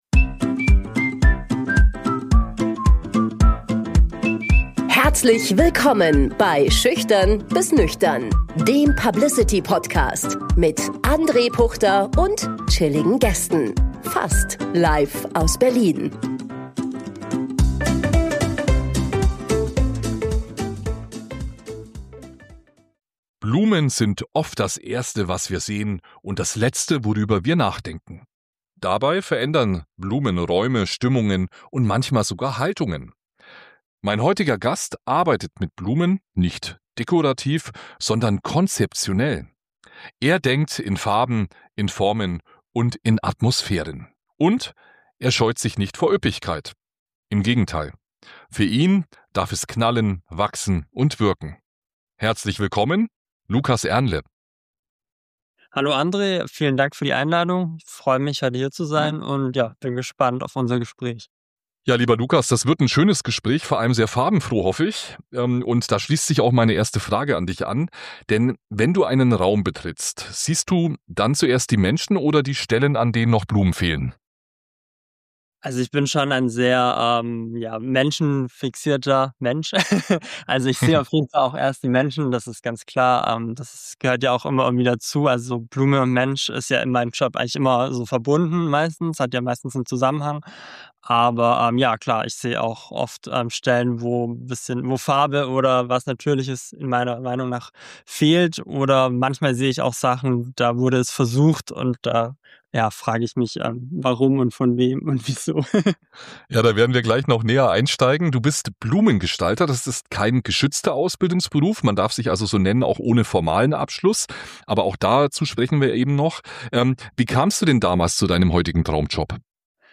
Ein Podcast über das Ungewöhnliche – und das, was uns verbindet.